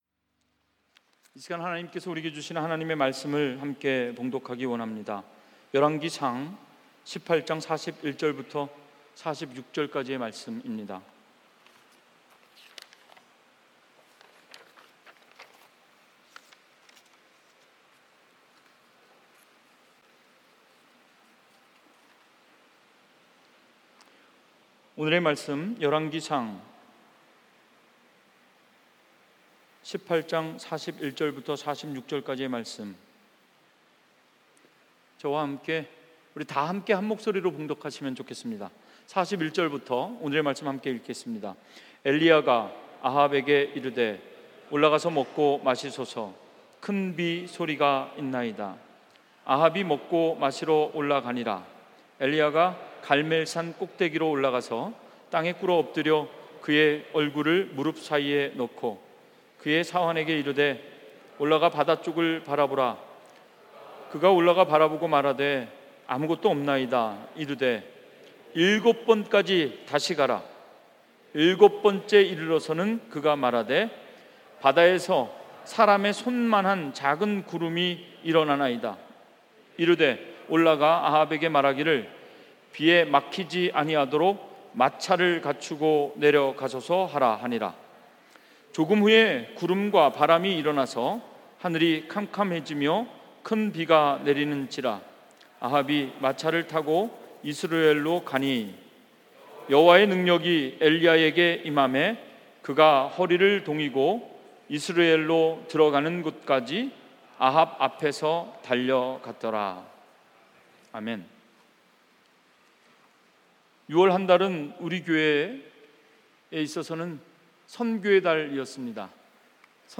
주일설교 - 2019년 07월14일 - 큰 비가 내리는지라 (A heavy rain came on)